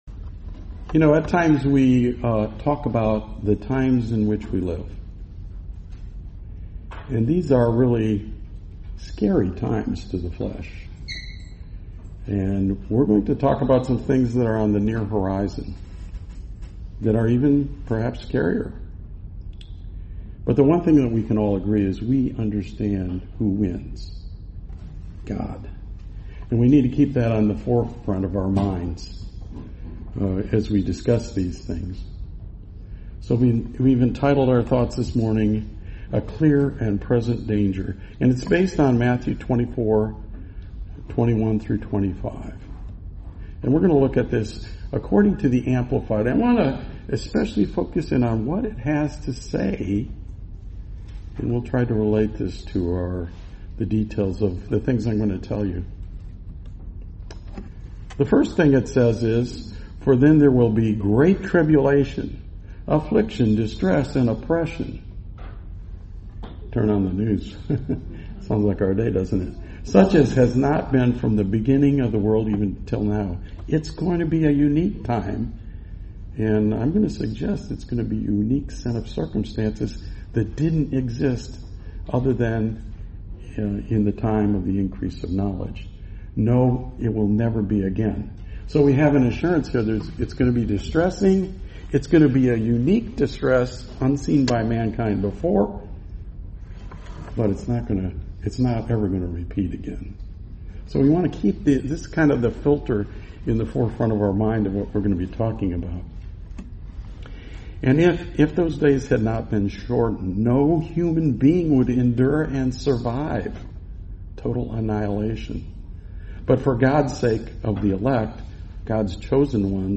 Series: 2025 Milwaukee Convention